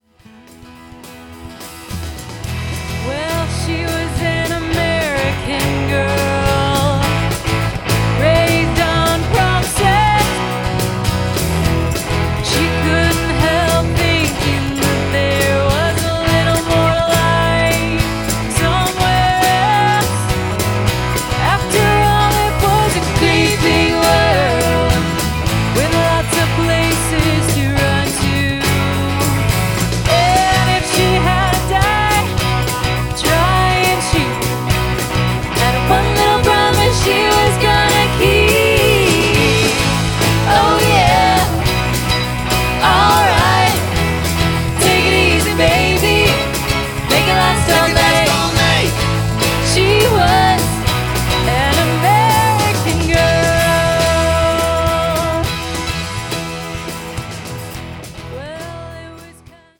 signature blend of harmonies and energy